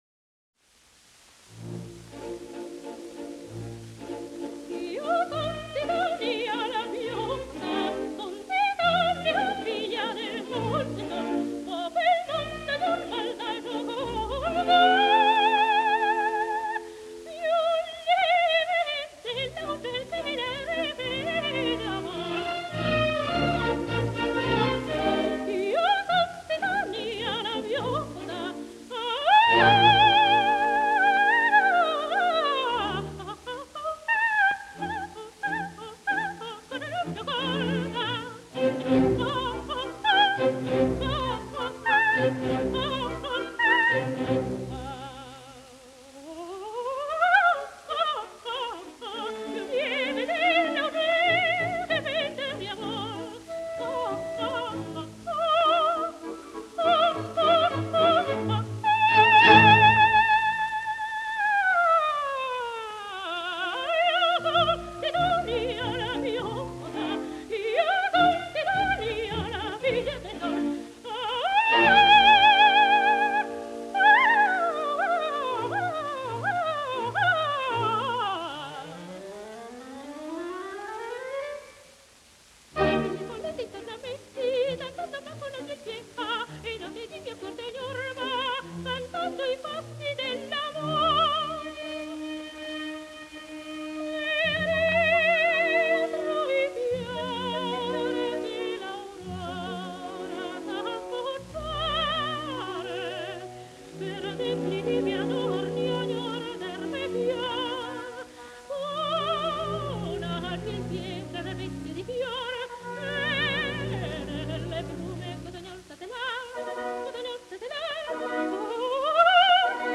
ДАЛЬ MОHTE (Dal Monte) Тоти (наст. имя и фамилия - Антониетта Mенегелли, Meneghelli) (27 VI 1893, Мольяно-Венето - 26 I 1975) - итал. певица (колоратурное сопрано).
Редкий по красоте тембра, гибкости и яркости звучания голос, виртуозное вок. мастерство, музыкальность и артистич. дарование поставили Д. М. в ряд выдающихся оперных певиц мира.